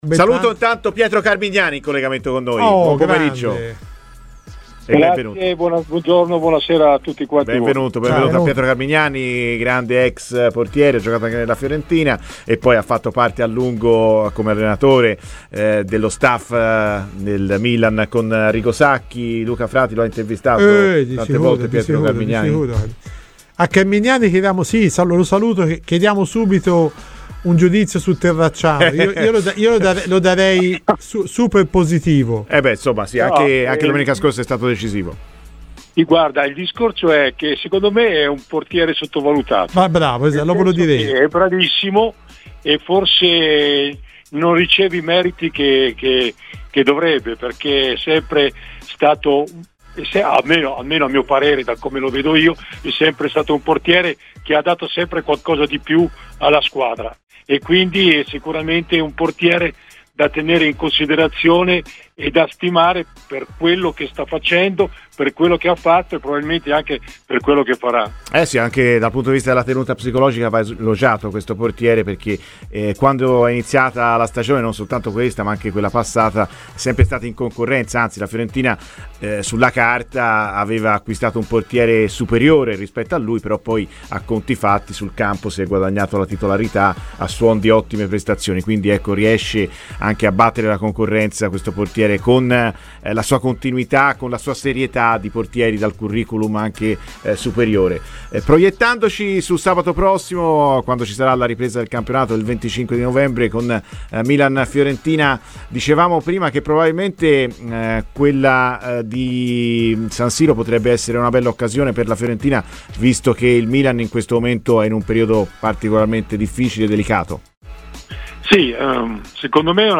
a Radio FirenzeViola durante "Viola Amore Mio" a partire dall'attuale estremo difensore gigliato